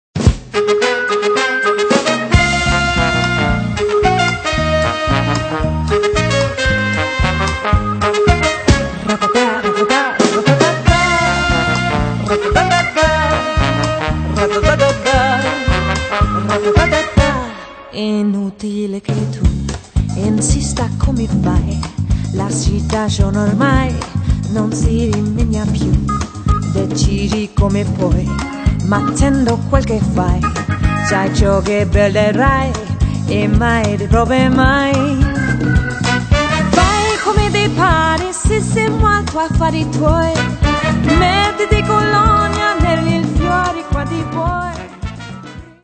leichtfüßig-temperamentvollen Stücken